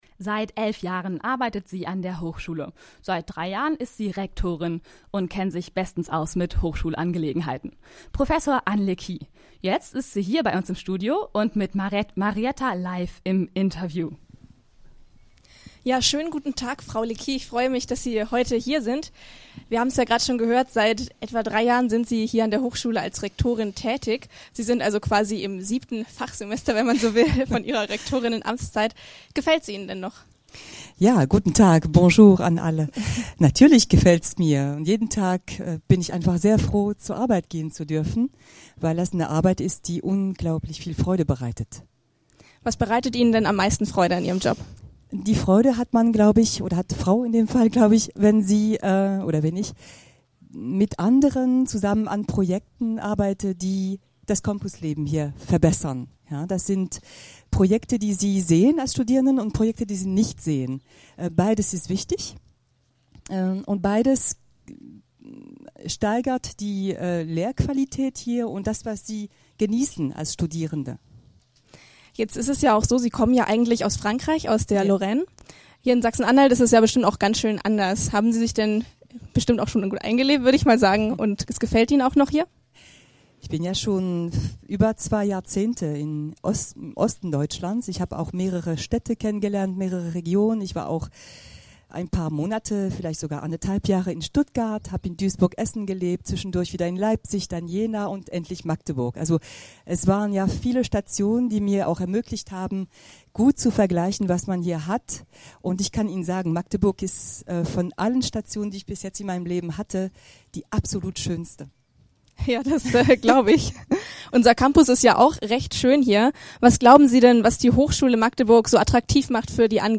in unserem Open Air Studio